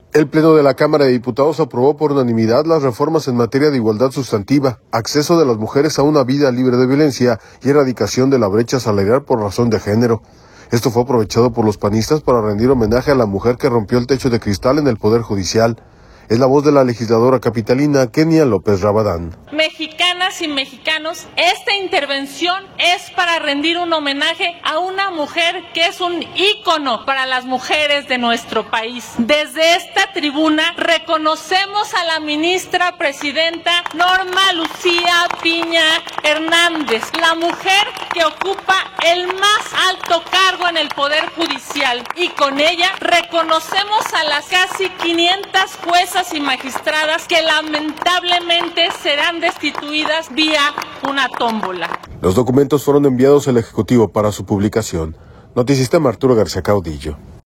audio El Pleno de la Cámara de Diputados aprobó por unanimidad las reformas en materia de igualdad sustantiva, acceso de las mujeres a una vida libre de violencia y erradicación de la brecha salarial por razón de género. Esto fue aprovechado por los panistas para rendir homenaje a la mujer que rompió el techo de cristal en el Poder Judicial, es la voz de la legisladora capitalina Kenia López Rabadán.